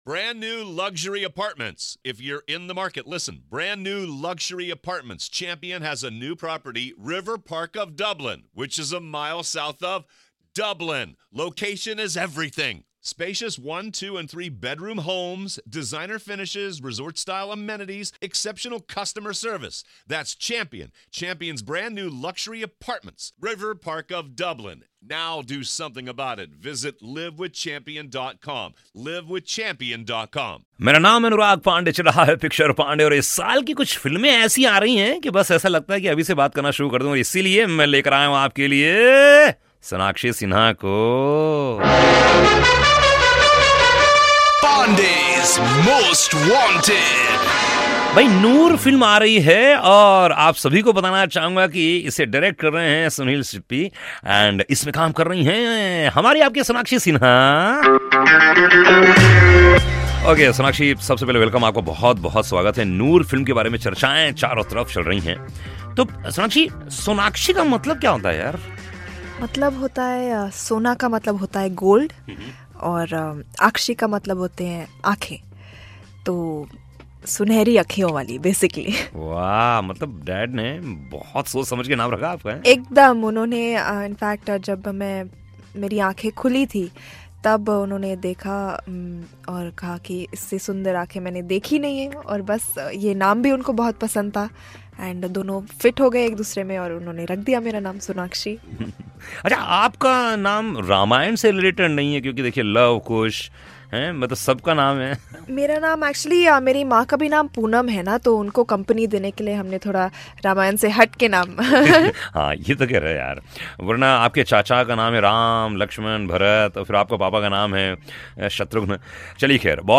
Interview of Movie Noor -Link 1